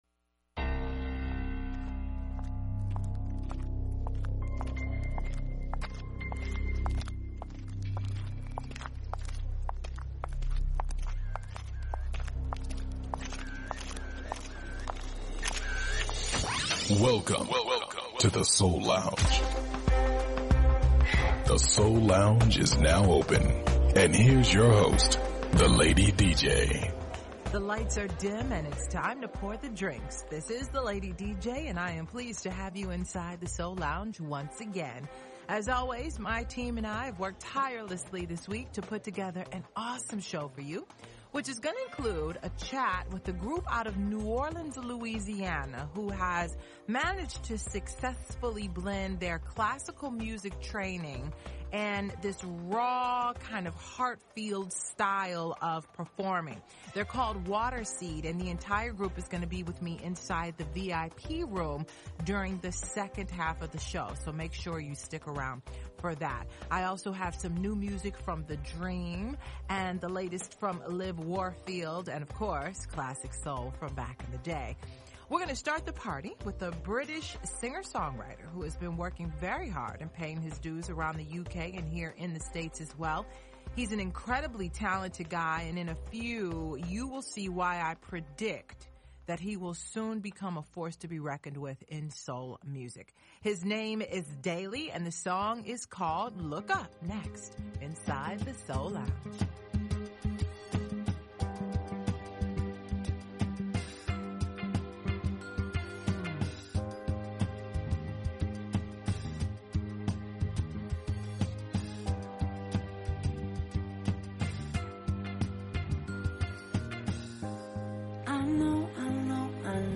music, interviews and performances
Neo-Soul
conscious Hip-Hop
Classic Soul